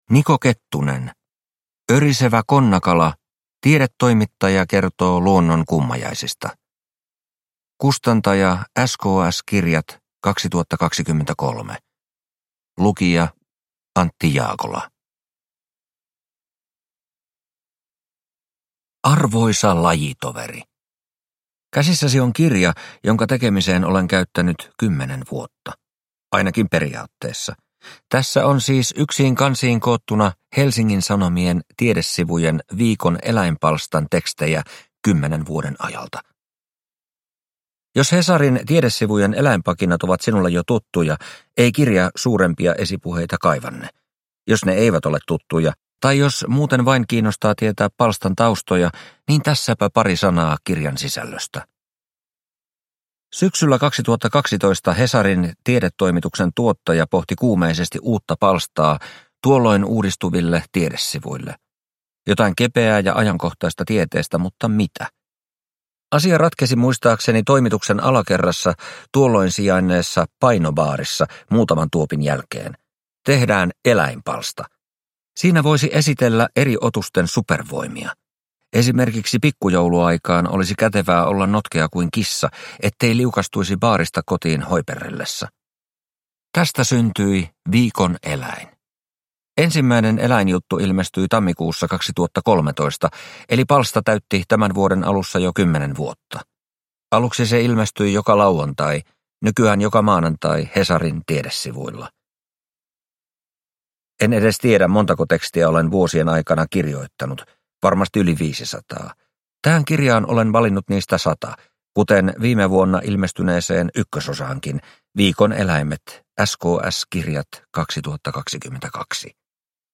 Örisevä konnakala – Ljudbok – Laddas ner